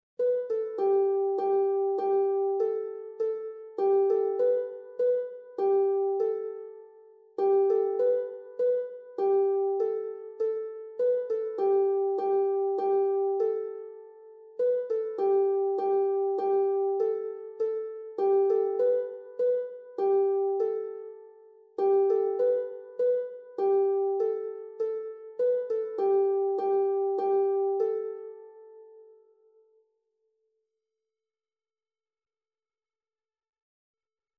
dit liedje is pentatonisch